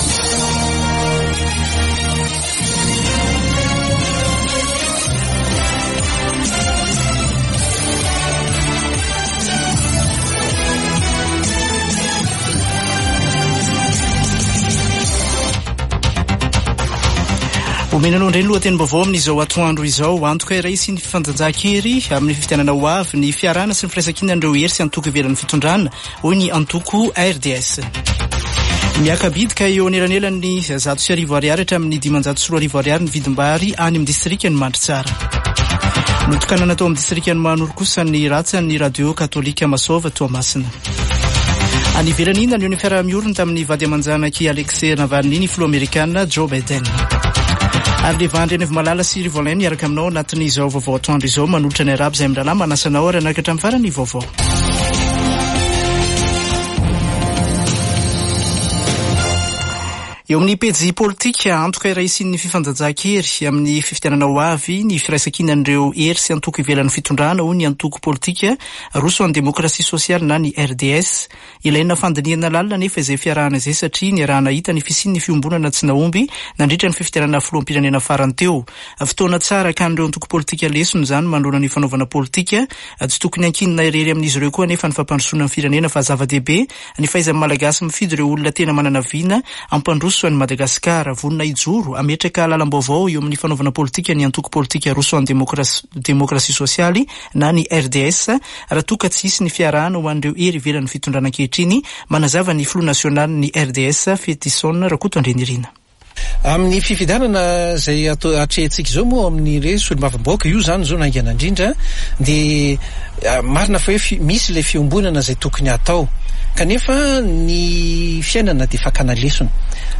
[Vaovao antoandro] Zoma 23 febroary 2024